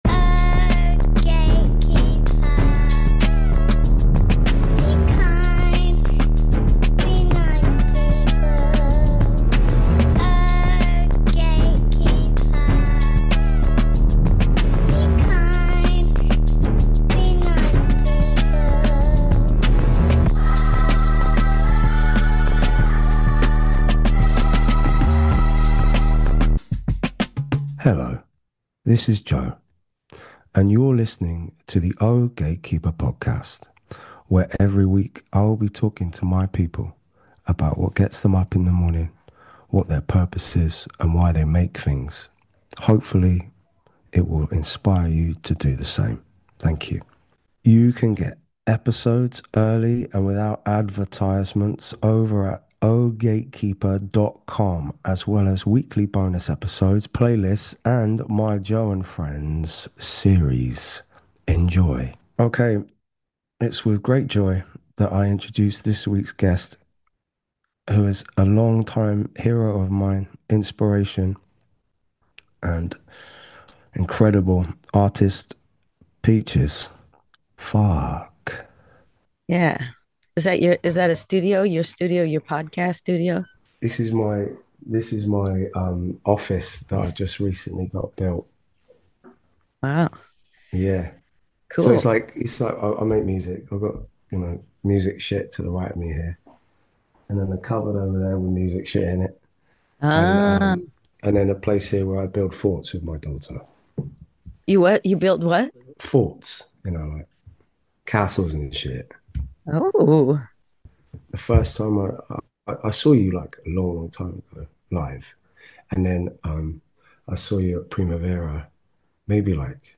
Each week Joe sits down for intimate one-to-one conversations with some of his favourite people in the world: be it artists, musicians or cultural icons, to explore their compulsion to create art.